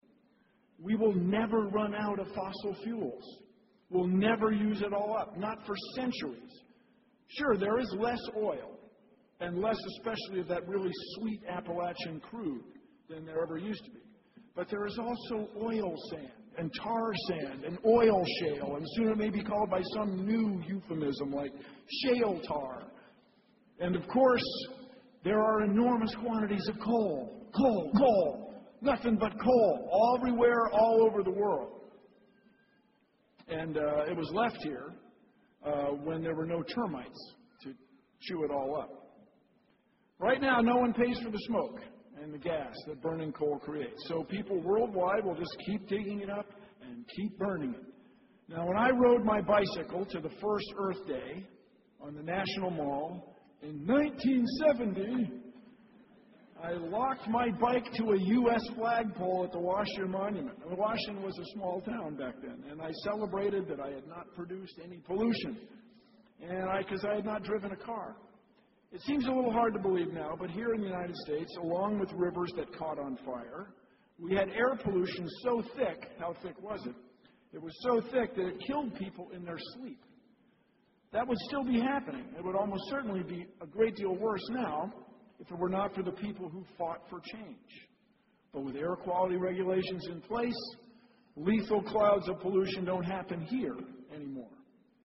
公众人物毕业演讲第160期:比尔·奈马萨诸塞大学2014(7) 听力文件下载—在线英语听力室